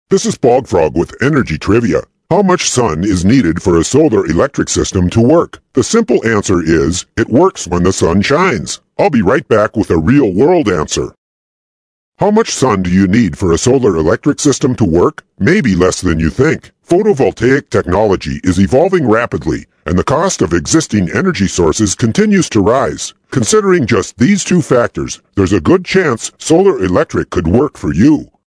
Bog Frog® Energy Trivia™ tips are fun, bite-size, radio features on energy conservation.
Bog Frog's voice is distinctive and memorable, while his messages remain positive and practical, in a memorable trivia format.